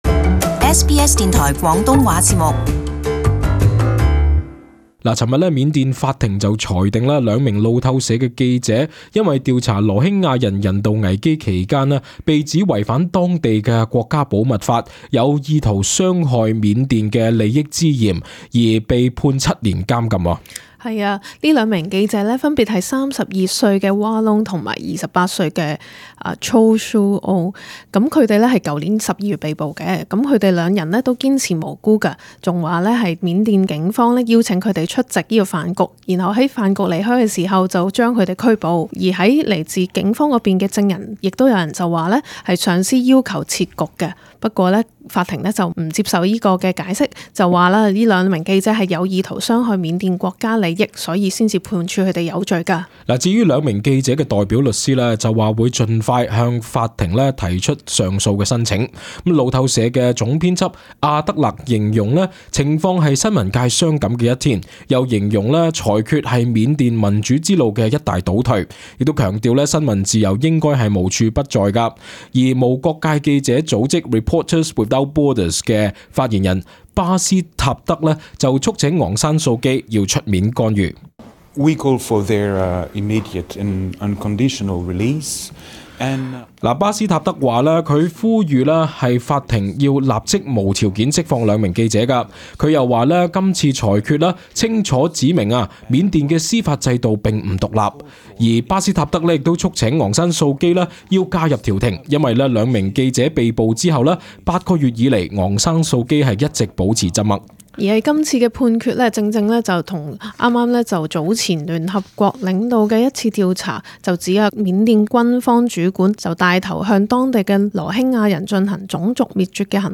【時事報導】兩路透社記者被緬甸法庭判監七年